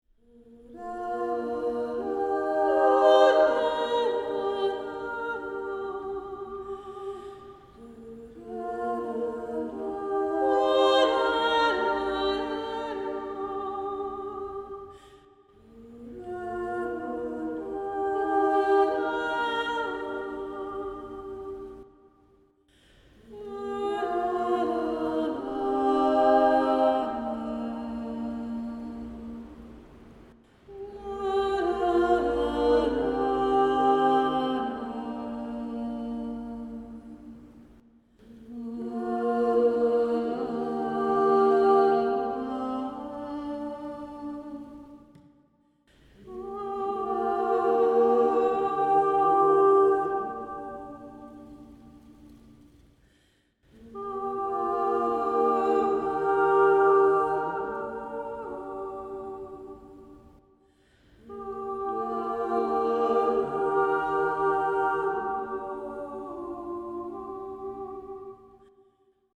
Over the two days, participants are introduced to the basic elements of improvised singing including melody, harmony, text, pulse and vocal percussion.
Sample files from Creative Voice workshops
Creative Voice Improv 1.mp3